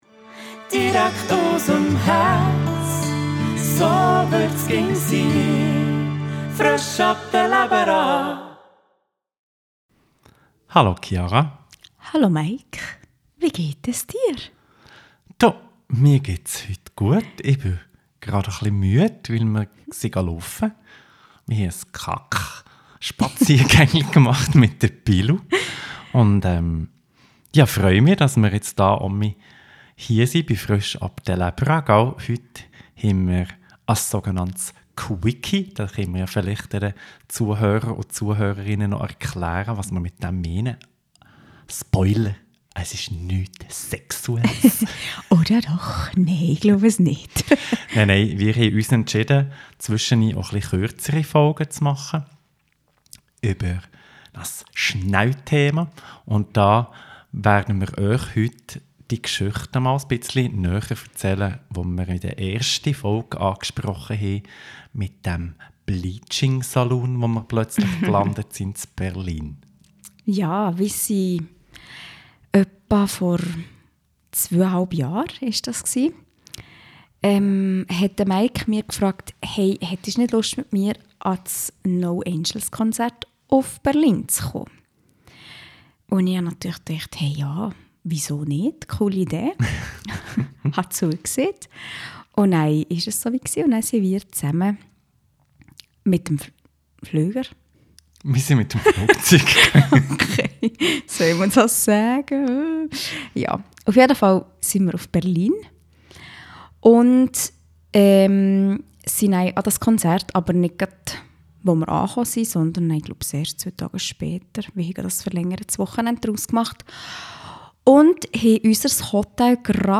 im Gspräch